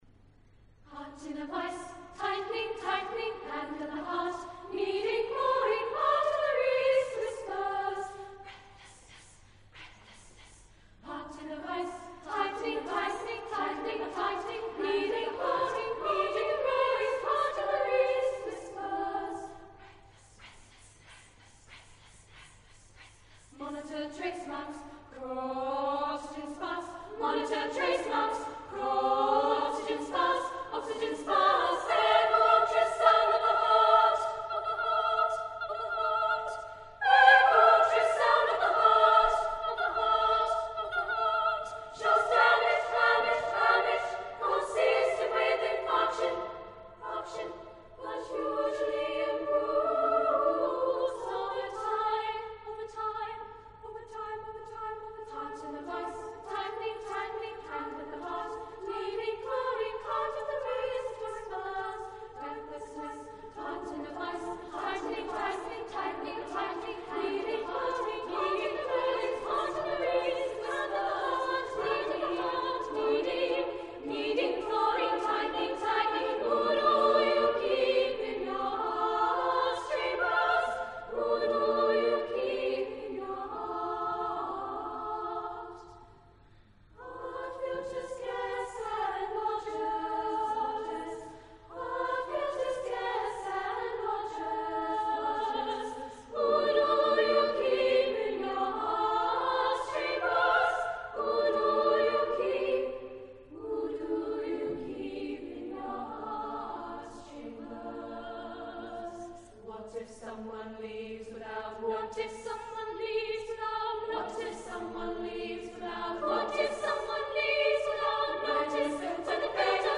for SSAA choir a cappella